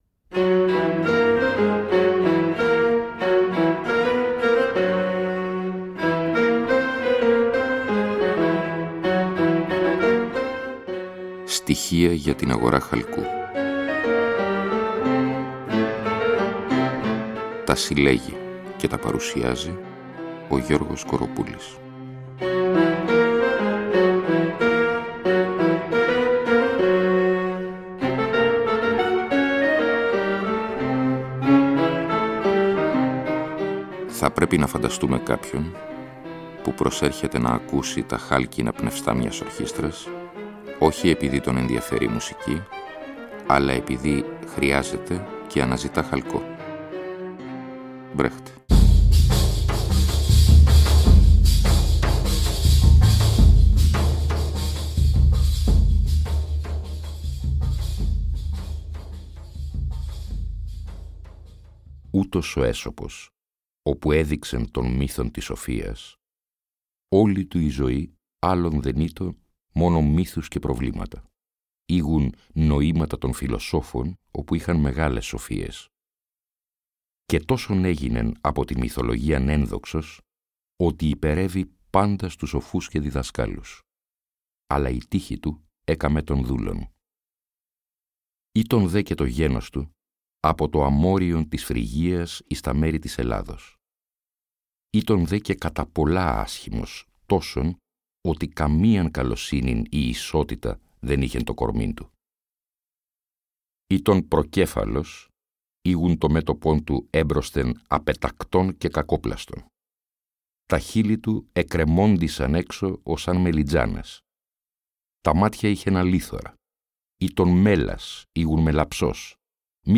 Εκπομπή λόγου.